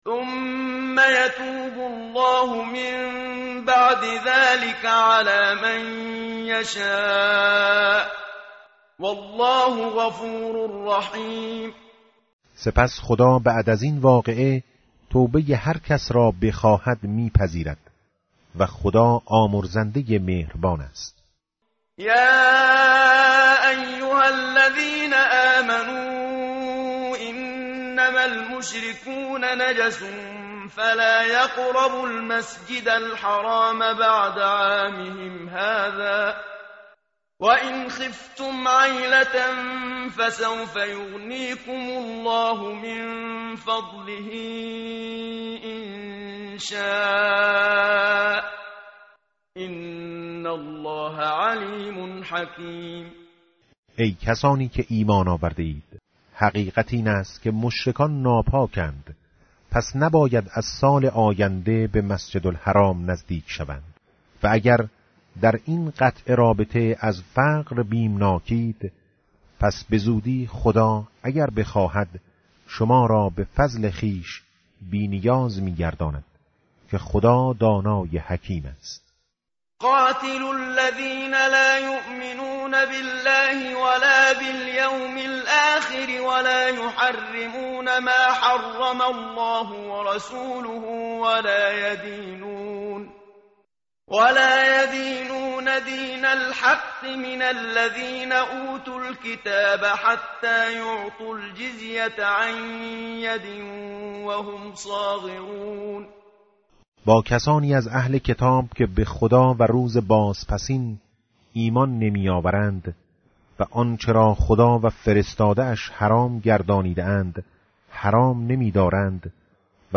tartil_menshavi va tarjome_Page_191.mp3